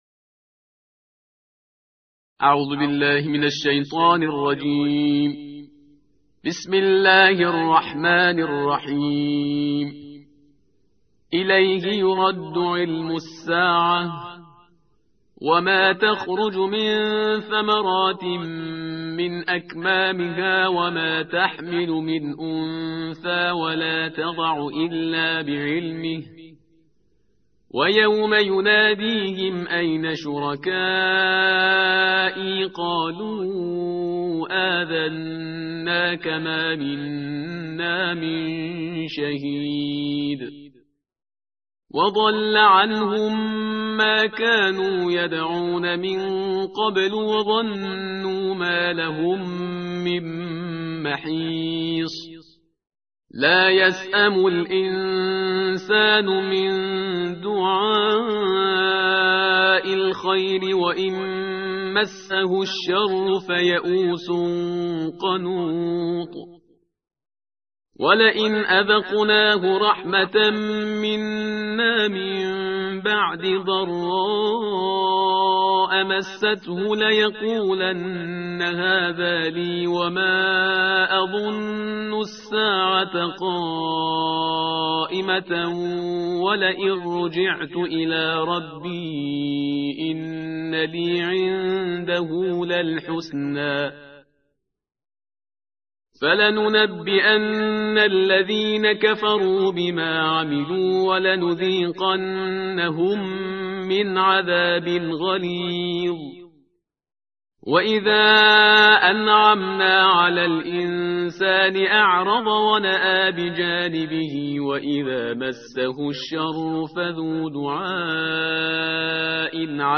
ترتیل جزء «بیست و پنجم» قرآن کریم